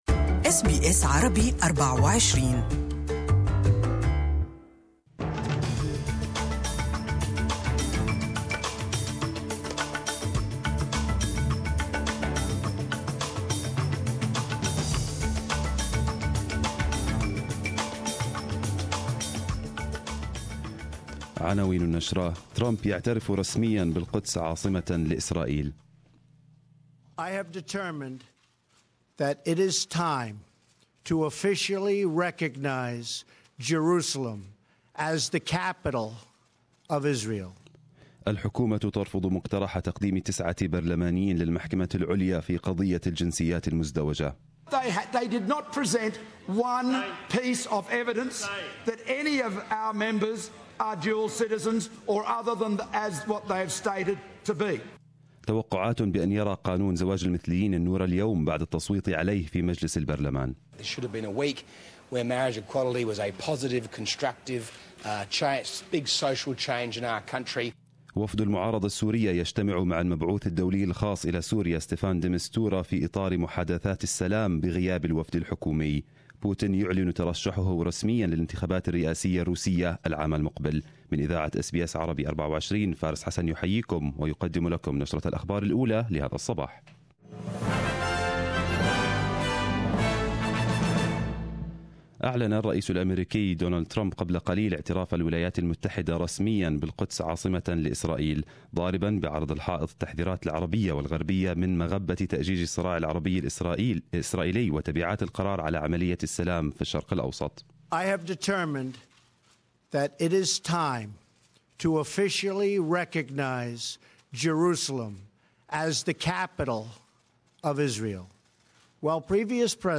Arabic News Bulletin 07/12/2017